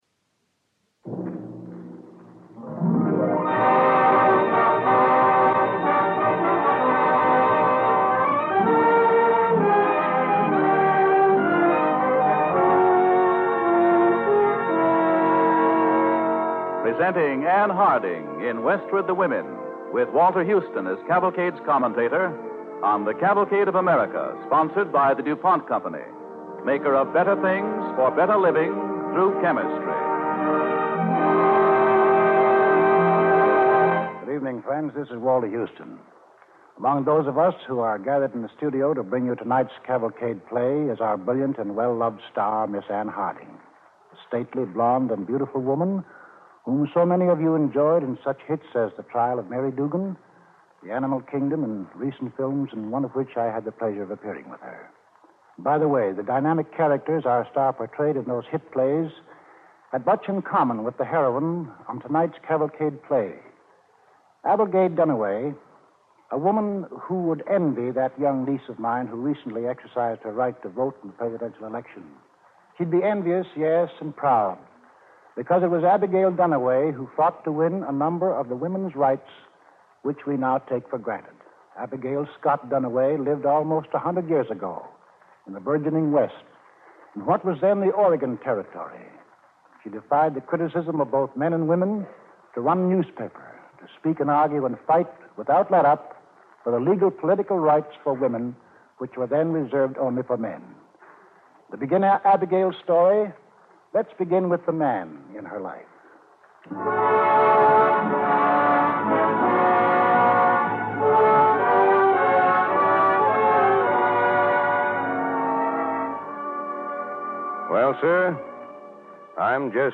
Cavalcade of America Radio Program Westward the Women, starring Ann Harding with host Walter Houston, January 1, 1945